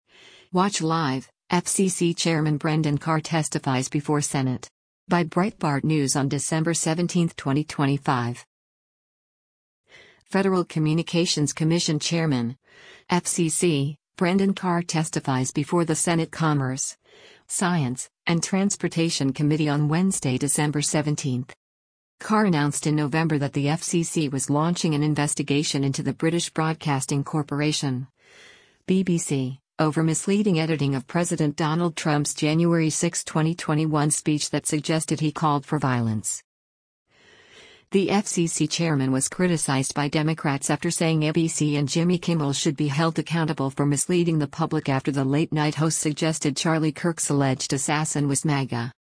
Federal Communications Commission Chairman (FCC) Brendan Carr testifies before the Senate Commerce, Science, and Transportation Committee on Wednesday, December 17.